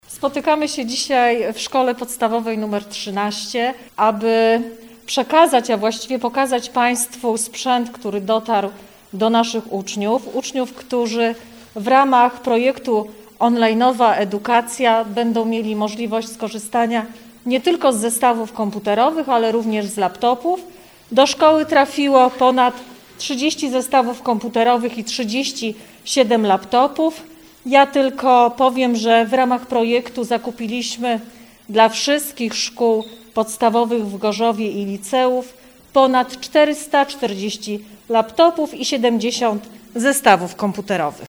mówiła podczas krótkiego spotkania w placówce wiceprezydent Małgorzata Domagała